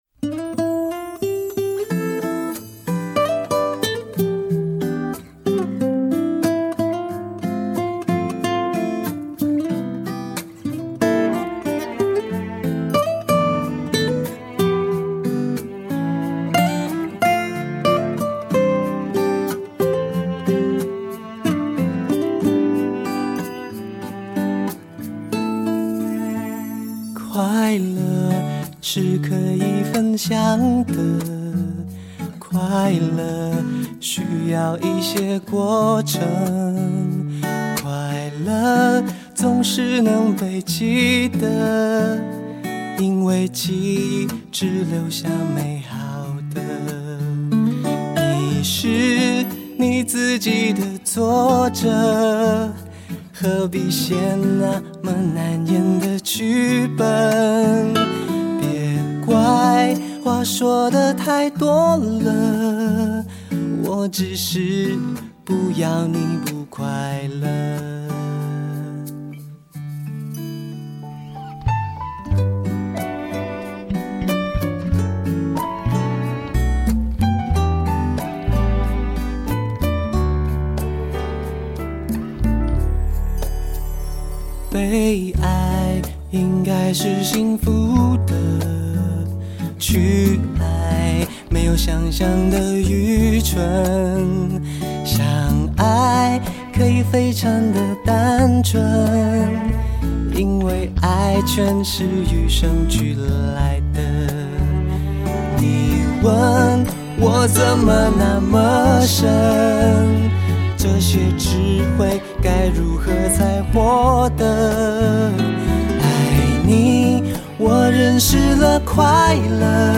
已推出过六张唱片的他，歌艺日益成熟和稳定，假音与感情也越来越纯熟。
风格清新轻快，欢乐的感觉满溢，听起来十分惬意